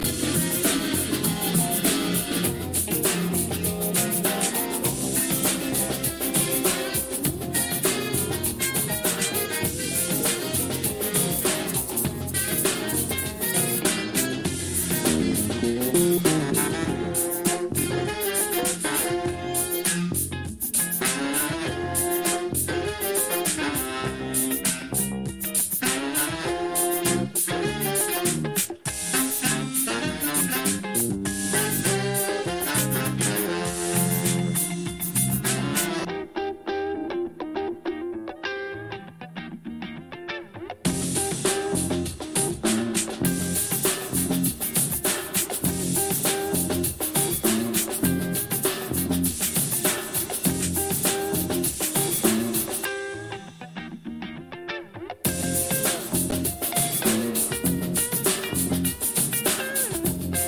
original funked up version